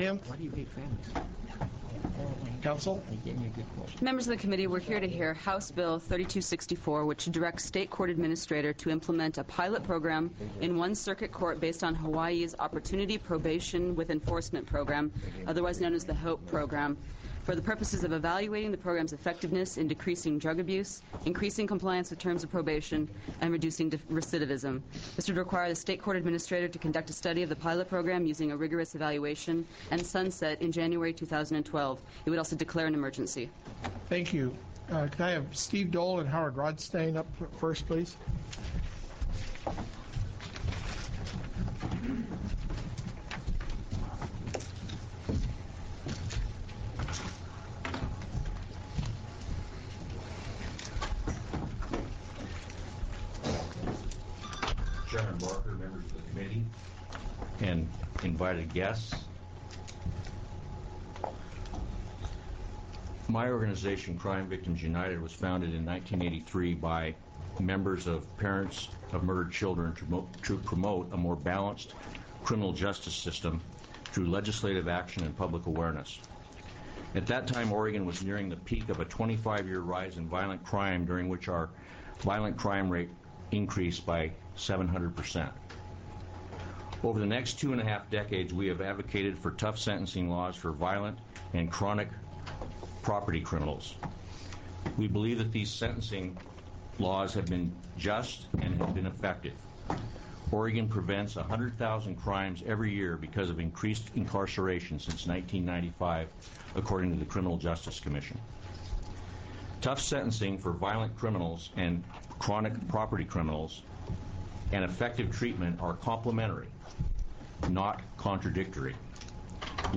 HOPE Program Hearing, April 10, 2009
Judge Alm starts at 09:12.